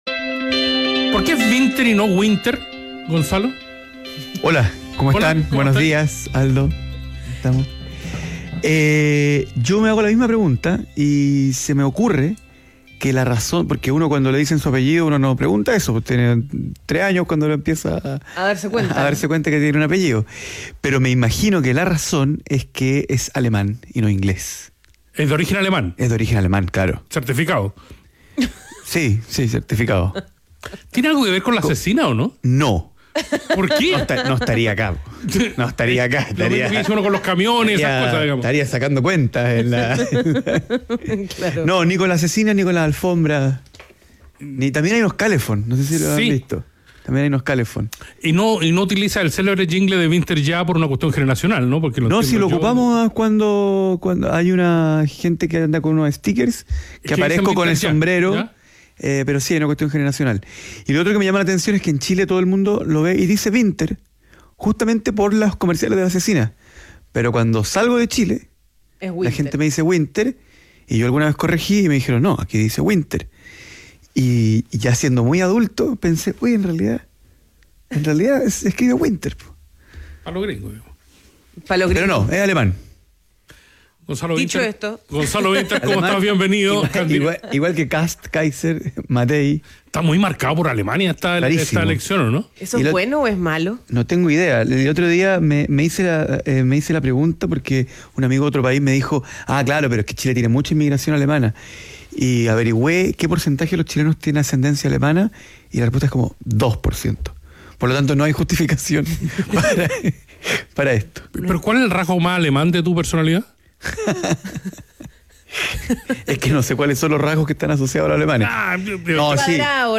Entrevista a Gonzalo Winter - País ADN